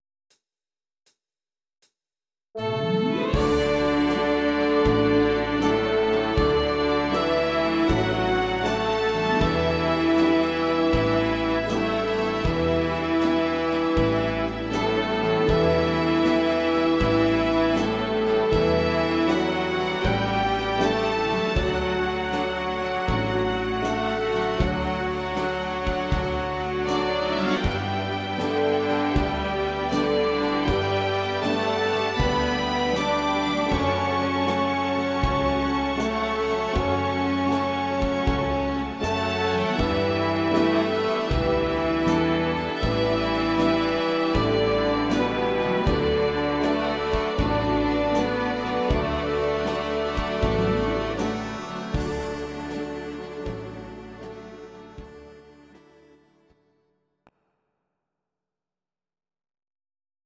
These are MP3 versions of our MIDI file catalogue.
Please note: no vocals and no karaoke included.
instr.Orchester